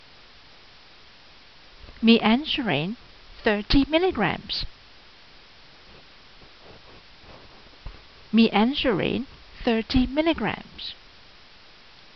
Pronunciation[edit]